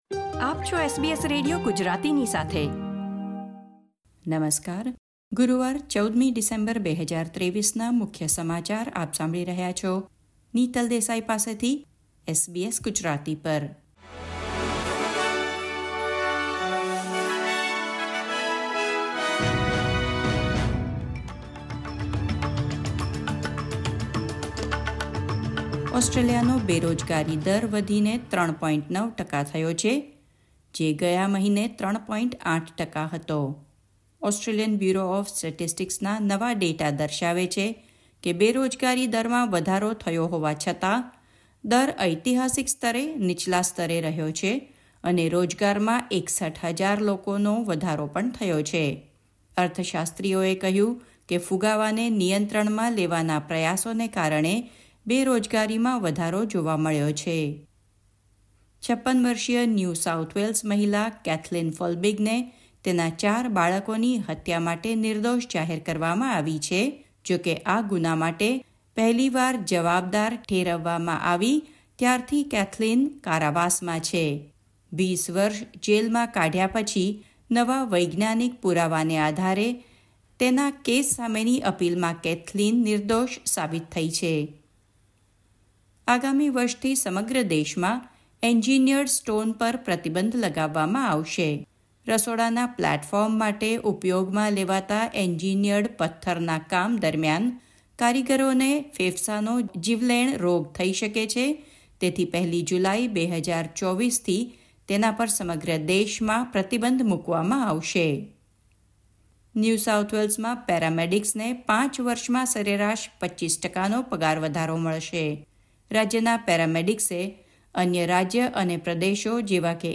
SBS Gujarati News Bulletin 14 December 2023